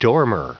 Prononciation du mot dormer en anglais (fichier audio)
Prononciation du mot : dormer